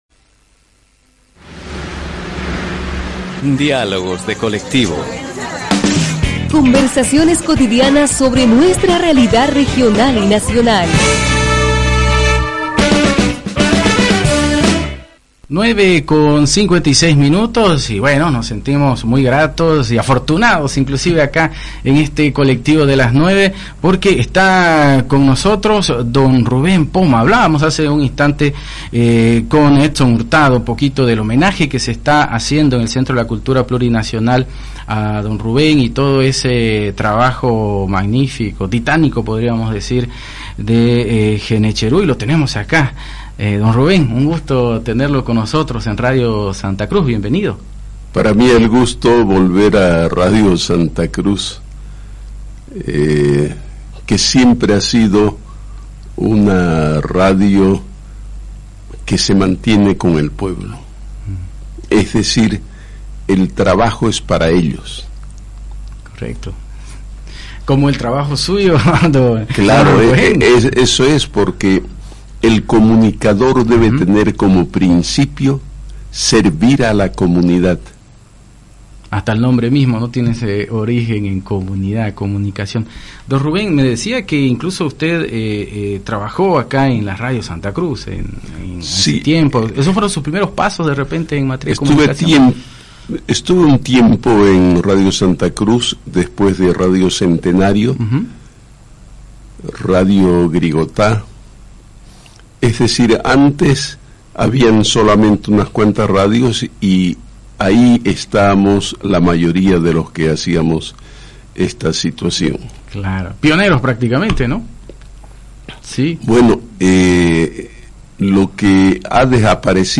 Recordamos la última entrevista de Rubén Poma en radio Santa Cruz
RUBEN-POMA-ENTREVISTA.mp3